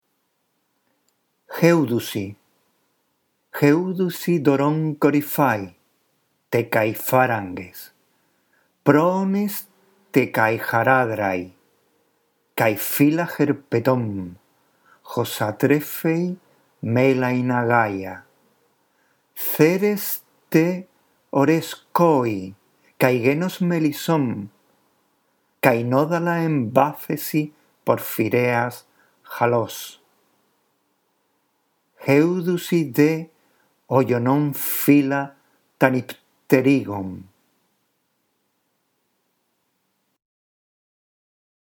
La audición de este archivo te ayudará en la práctica de la lectura del griego: